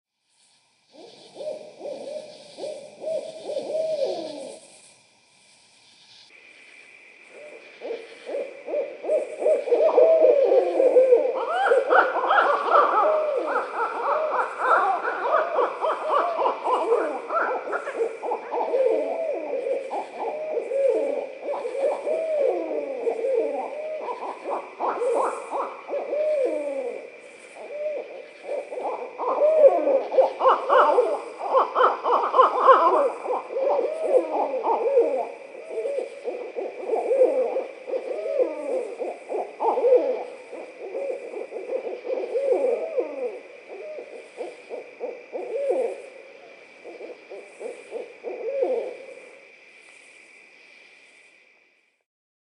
Forêt en Colombie Britannique
Barred-owls-laughing.mp3